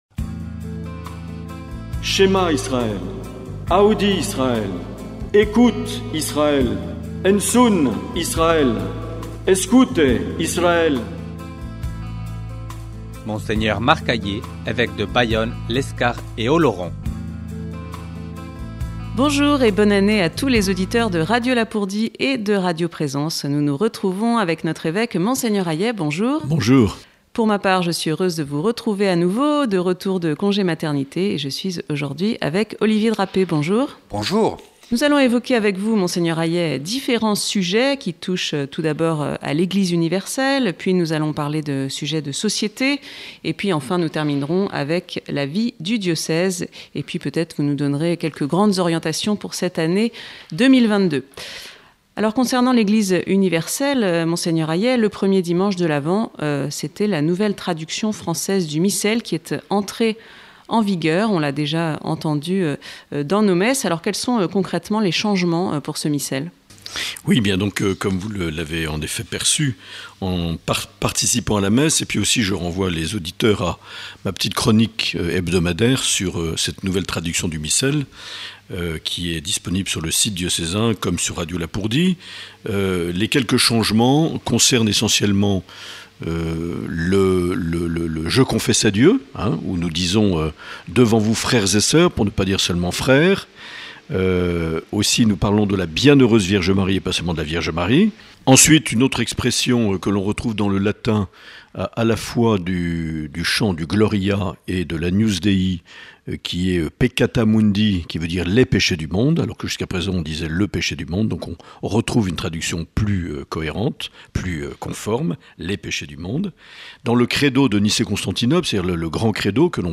L’entretien avec Mgr Aillet - Janvier 2022